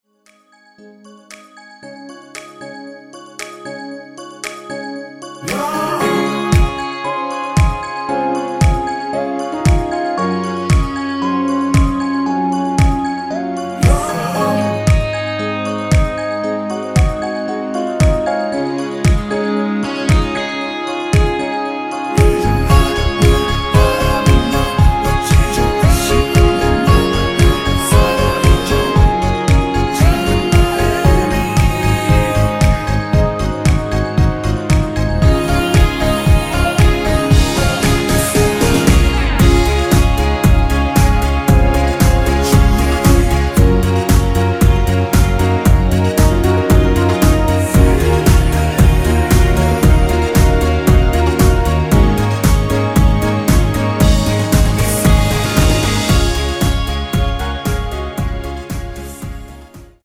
-2)코러스 MR 입니다.
워어~ 등 순수 코러스만 있습니다.
엔딩이 페이드 아웃이라 노래 부르기 좋게 엔딩 만들었습니다.
Db